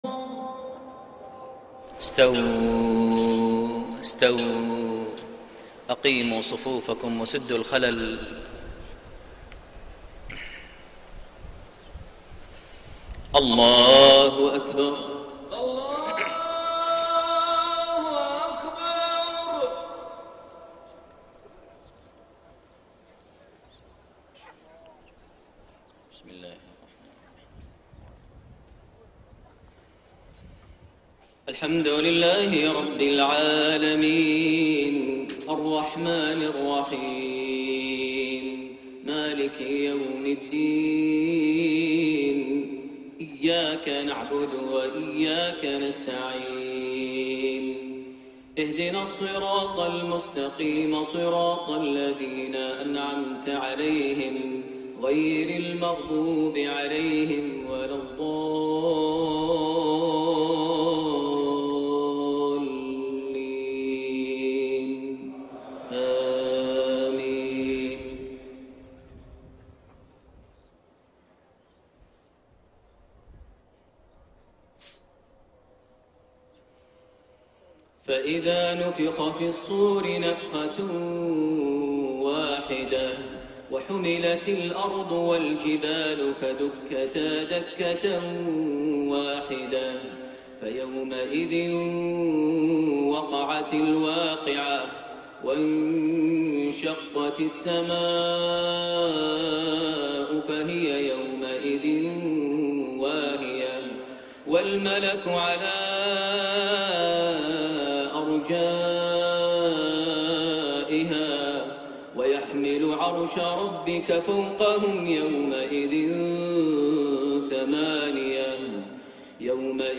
Maghrib prayer from Surah Al-Haaqqa > 1433 H > Prayers - Maher Almuaiqly Recitations